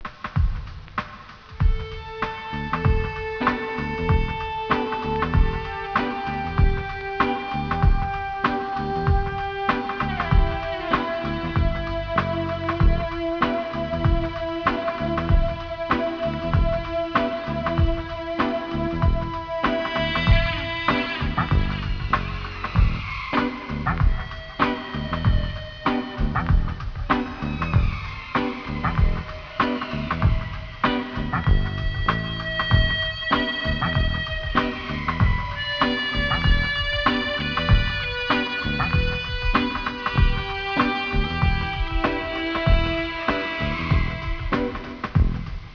Guitar, Effects, Percussion
Didjereedoo
Samples and Sounds
Samples and Beats
Mermaid Goddess Vocals
• Orca whale (A5 Pod - Corky's Family) - orca sounds